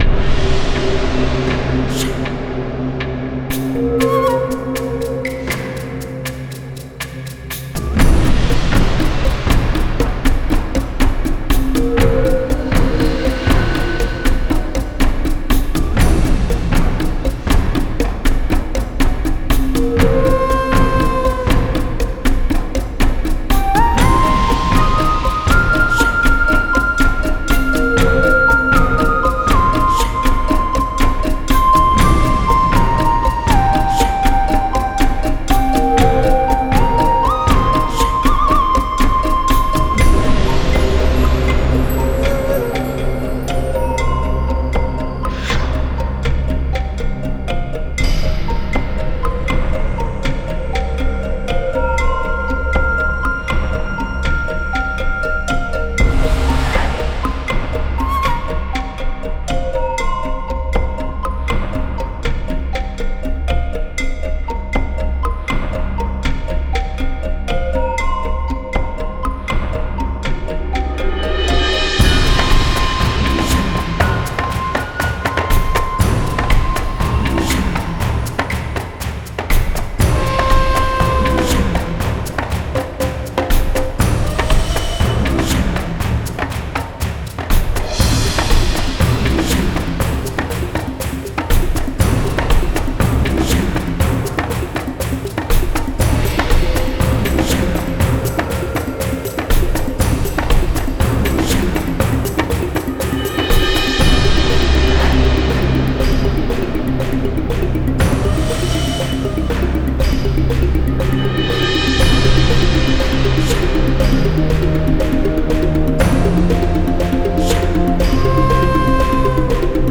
Ethnic ancient aztecs\maya music style.